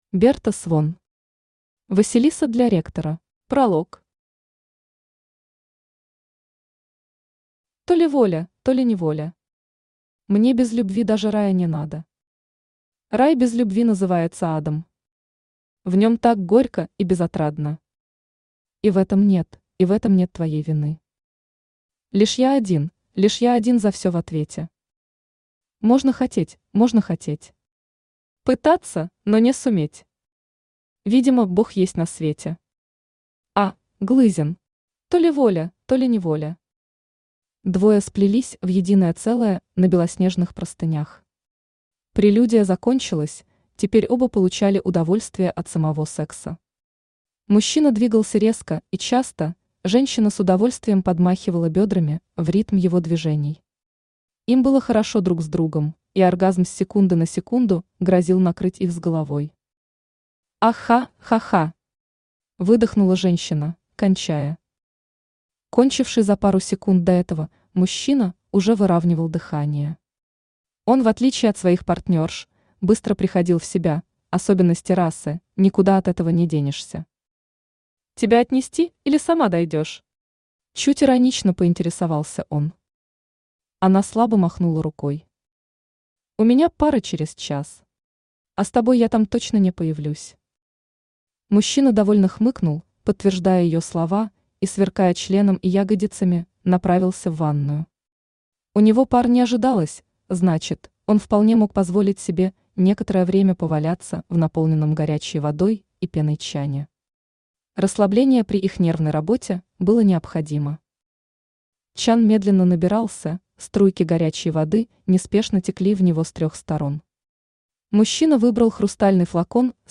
Aудиокнига Василиса для ректора Автор Берта Свон Читает аудиокнигу Авточтец ЛитРес.